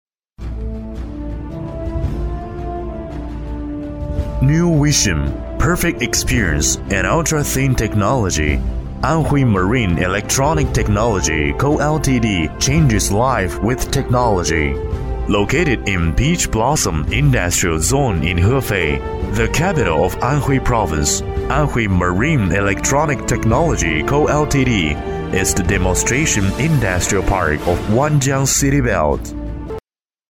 男40号配音师
十余年从业经验，精通中文，日文，英文，声音浑厚，庄重，大气。
英文-男40-企业介绍 英文.mp3